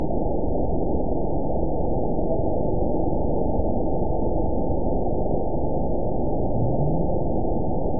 event 922752 date 03/25/25 time 18:33:25 GMT (2 months, 3 weeks ago) score 9.63 location TSS-AB01 detected by nrw target species NRW annotations +NRW Spectrogram: Frequency (kHz) vs. Time (s) audio not available .wav